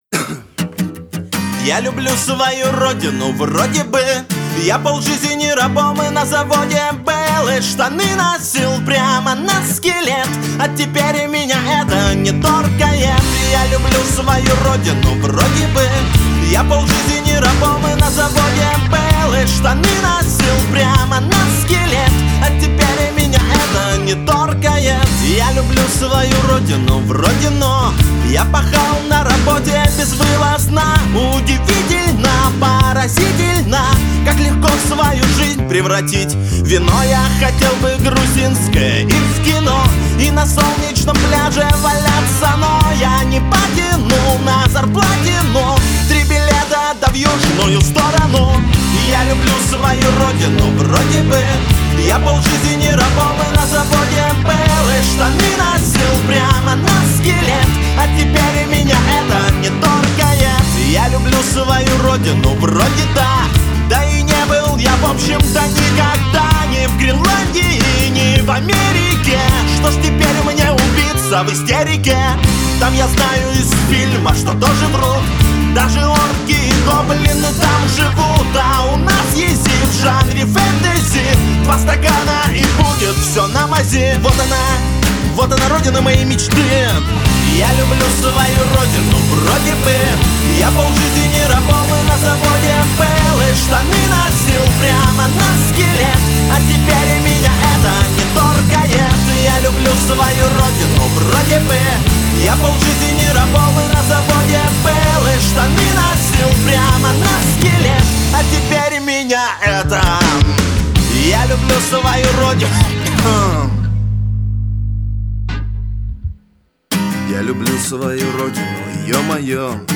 Рок музыка
русские рок песни